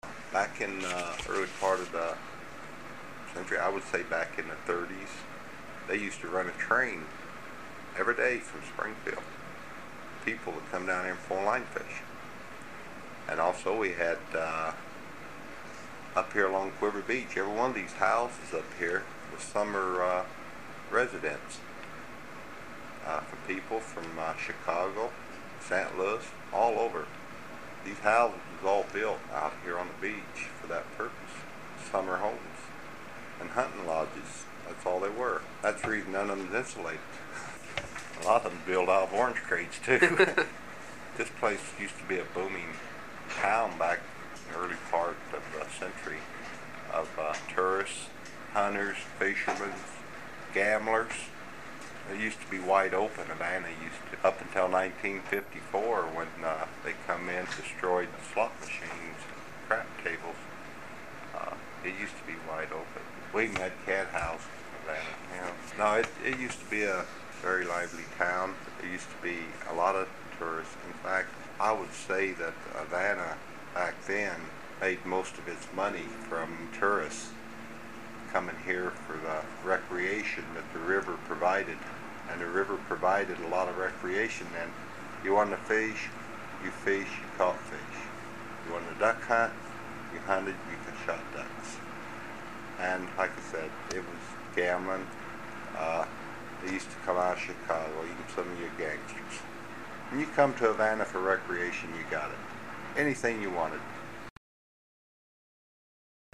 HTR Oral History, 08/18/1